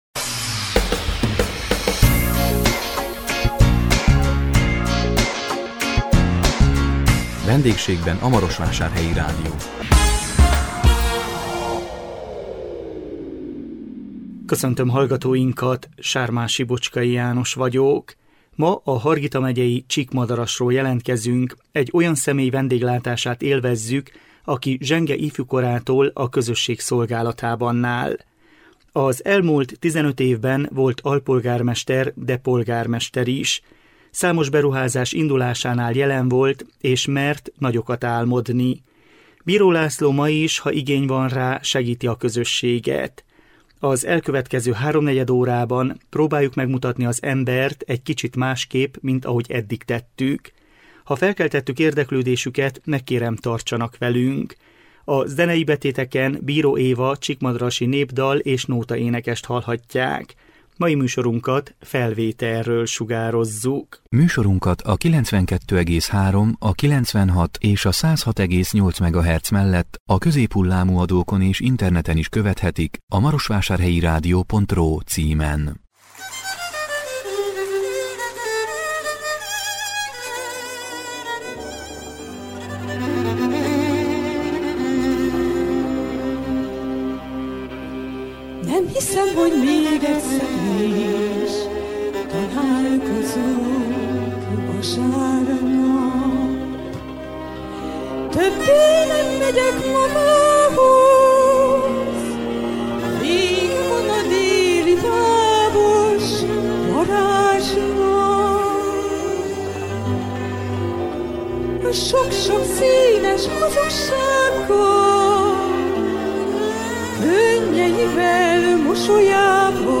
A 2021 december 9-én jelentkező VENDÉGSÉGBEN A MAROSVÁSÁRHELYI RÁDIÓ című műsorunkkal a Hargita megyei Csíkmadarasról jelentkeztünk. Egy olyan személy vendéglátását élveztük, aki zsenge ifjú korától a közösség szolgálatában áll. Az elmúlt 15 évben volt alpolgármester, de polgármester is.